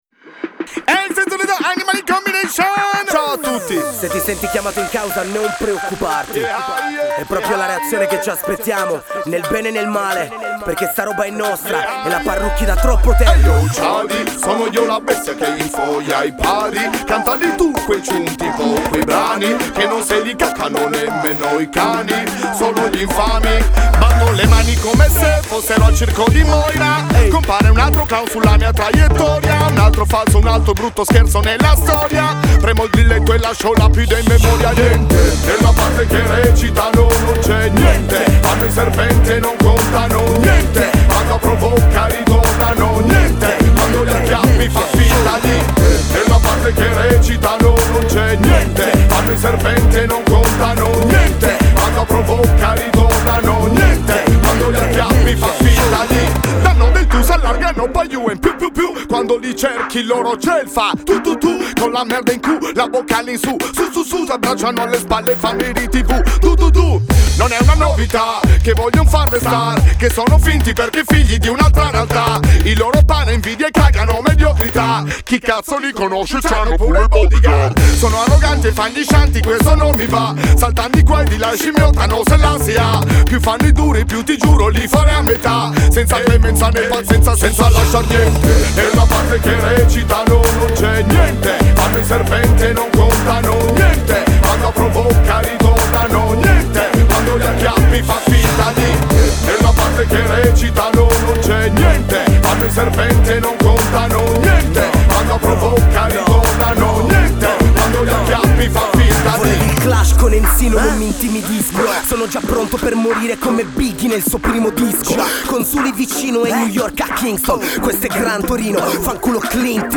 reggae italiano